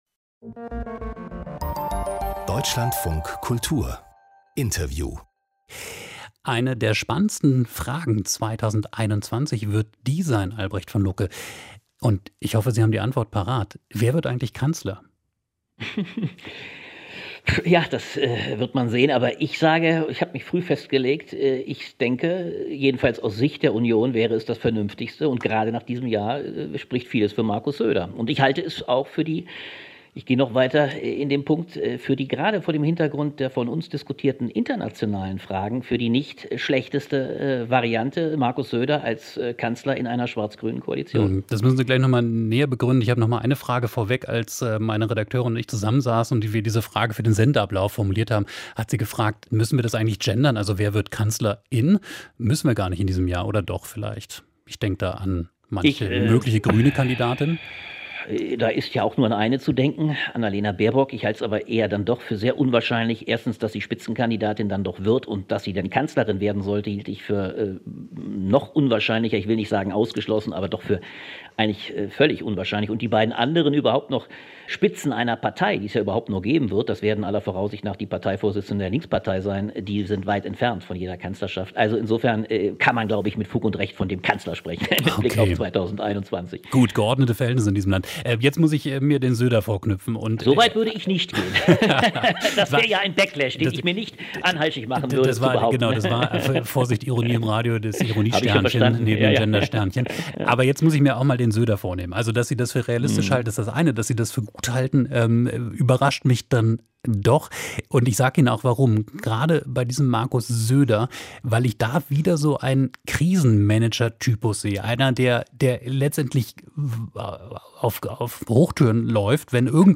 Die Themen des Tages mit einem Gast aus Medien, Kultur oder Politik: Das ist die Mischung von... Mehr anzeigen